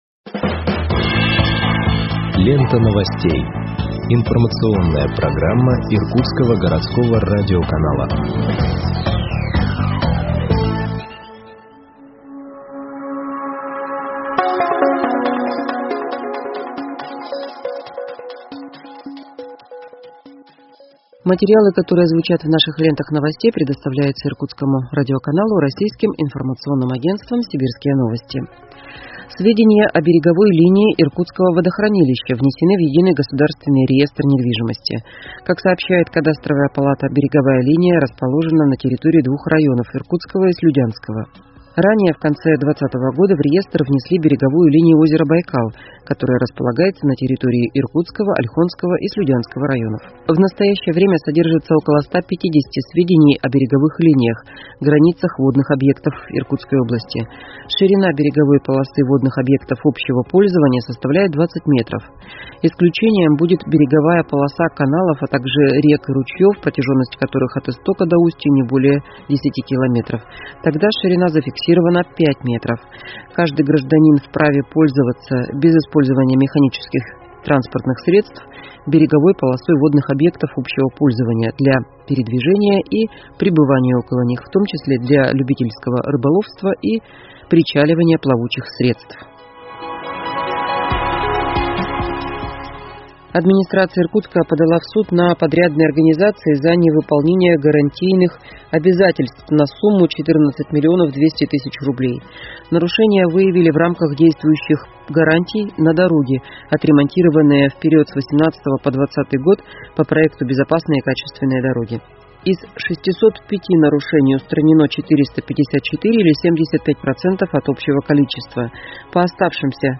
Выпуск новостей в подкастах газеты Иркутск от 15.10.2021